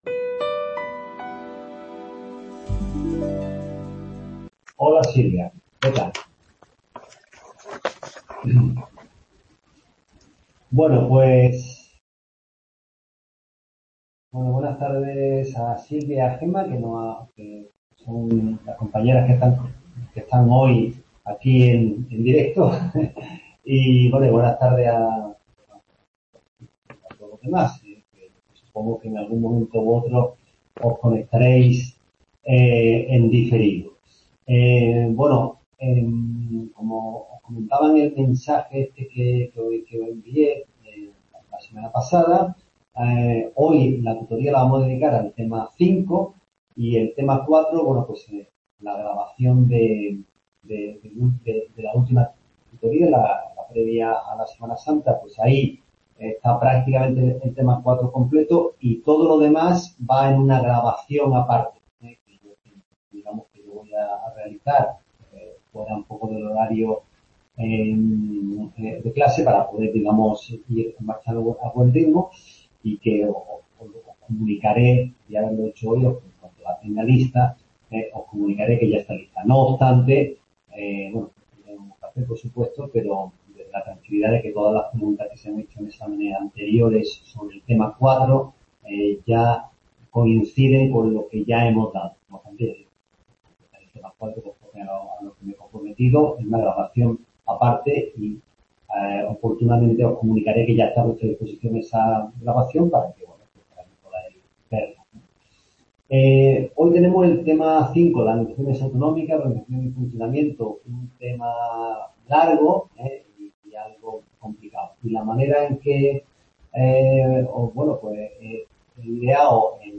Tutoría sobre el Tema 5 (Administraciones Públicas en España), estructurada a través de la respuesta a las preguntas test sobre el tema de exámenes de varios cursos pasados.